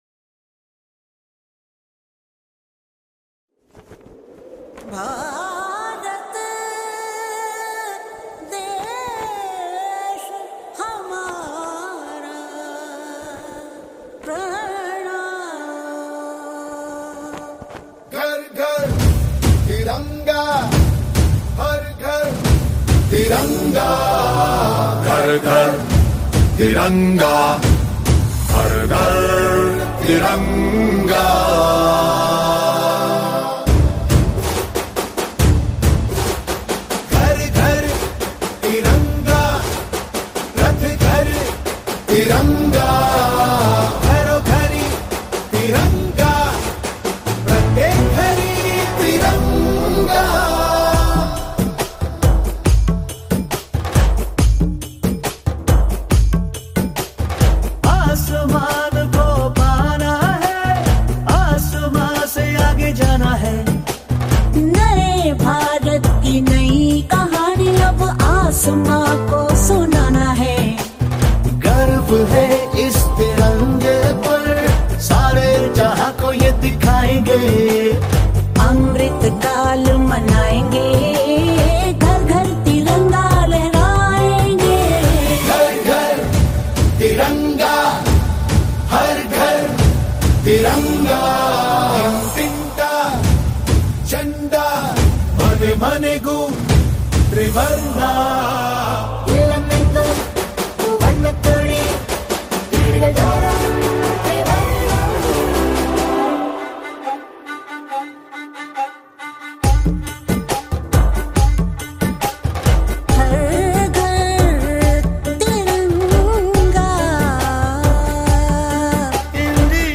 Desh Bhakti Songs